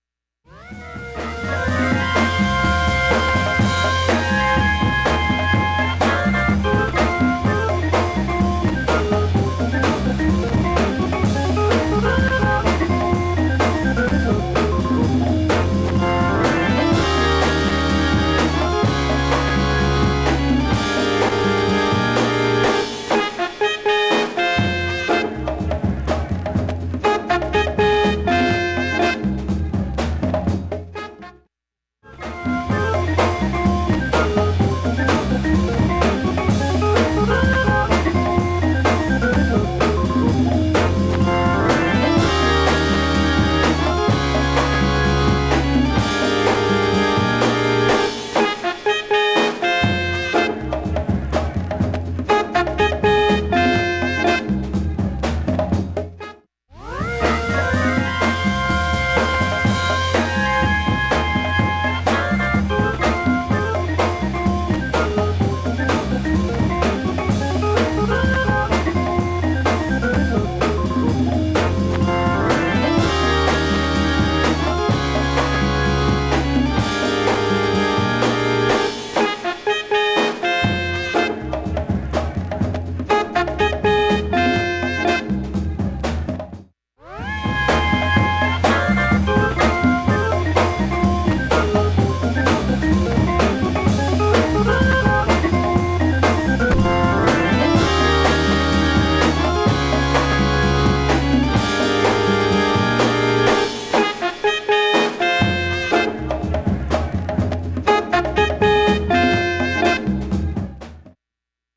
B-1中盤に針飛び補修あり、テクニクスMKプレーヤ、